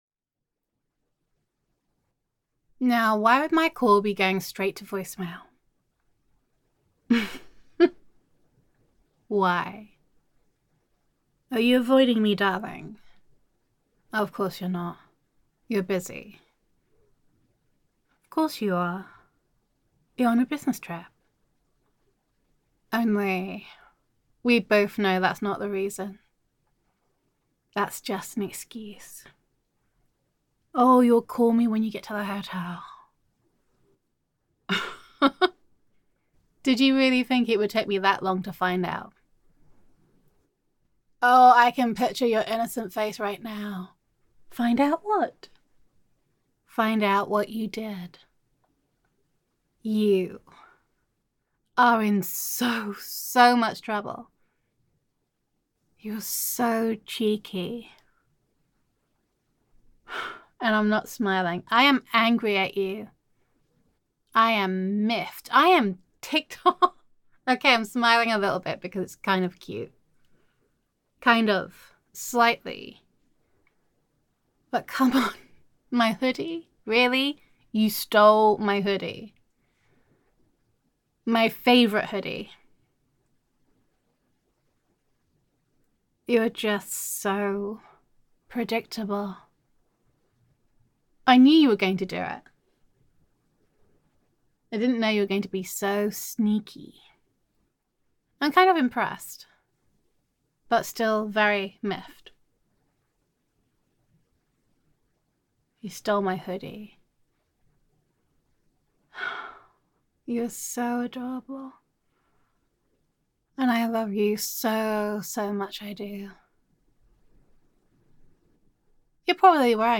[F4A] I Know What You Did [Girlfriend Roleplay][Girlfriend Voicemail][Sneakiness][Grand Theft Hoodie]Gender Neutral][Your Girlfriend Leaves You a Message While You Are on a Business Trip]